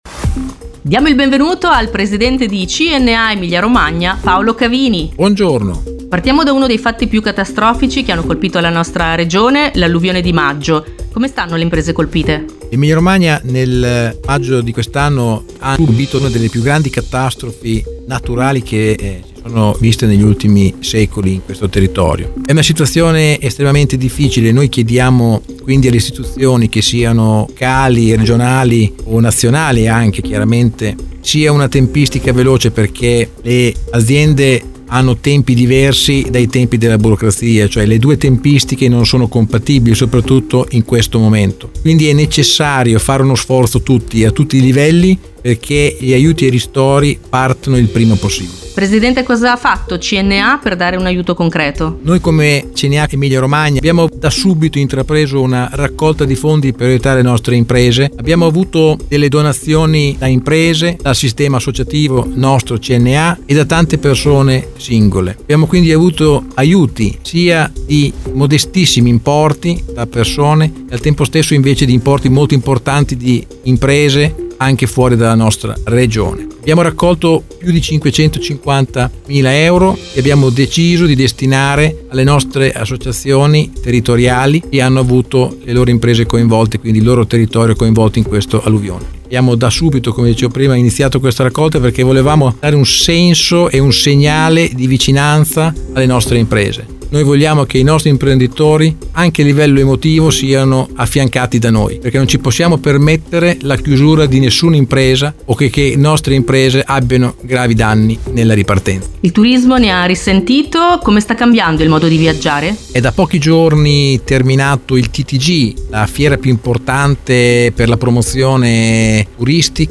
Intervista nei nostri studi a: